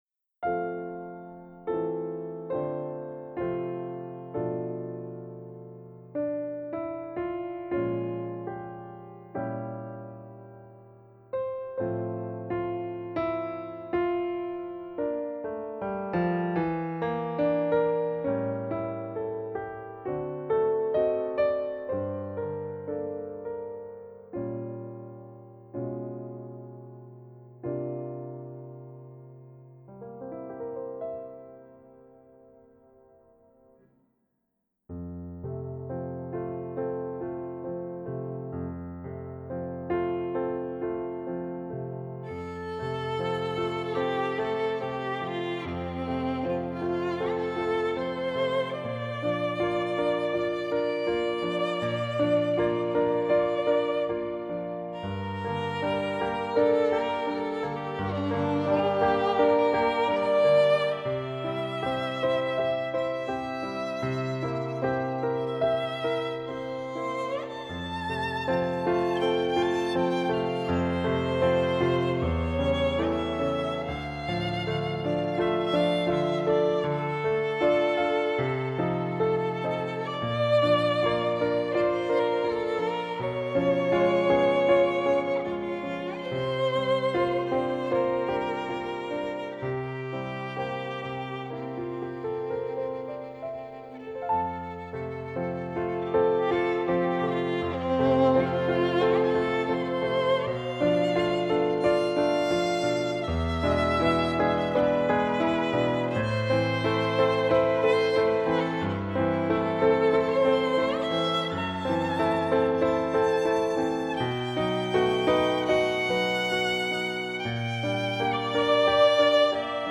В прошлом году мне пришла в голову идея развить свою давнюю фортепианную мелодию в духе вокализа.
Показываю пробную аранжировку мелодии в базовой версии семплерные скрипка + фортепиано